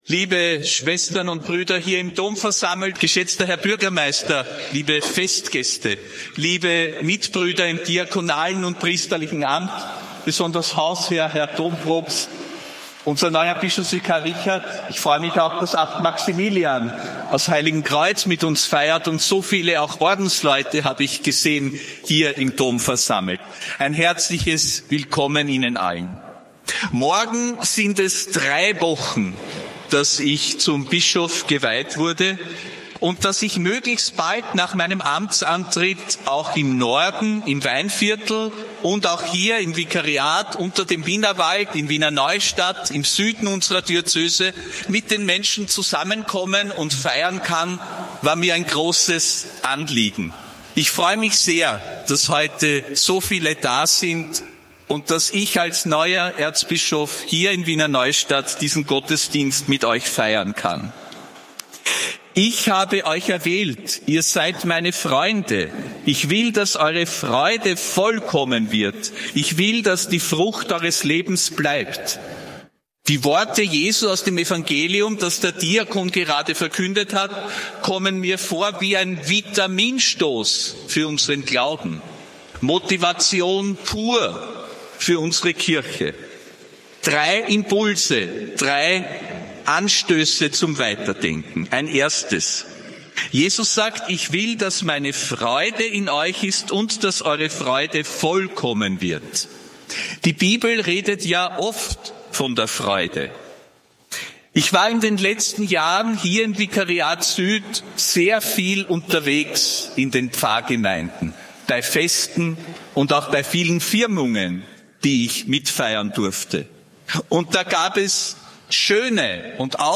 Predigt von Erzbischof Josef Grünwidl bei der Bischofsmesse im Dom zu Wr. Neustadt, am 13. Februar 2026.